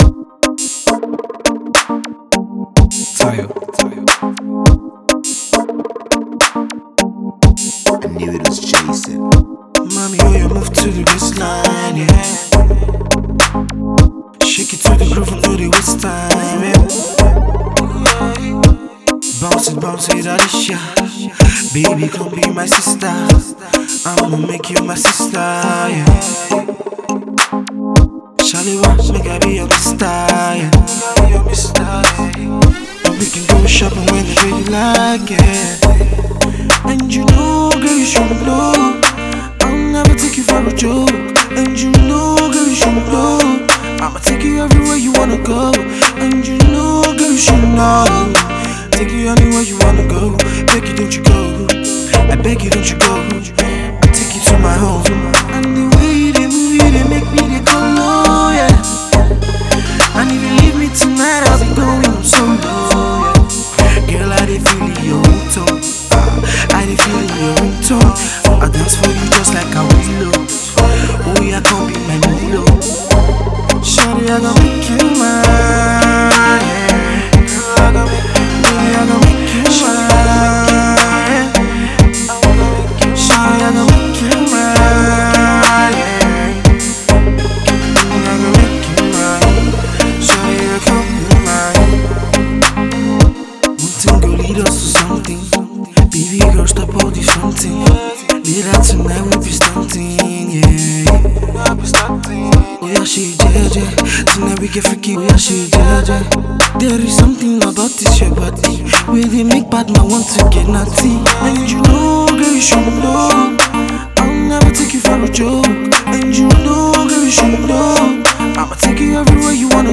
a love song in his signature laid-back tune.